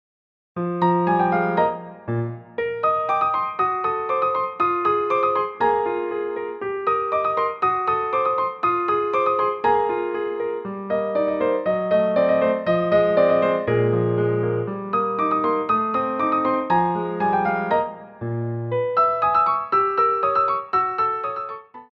Petit Allegro
2/4 (16x8)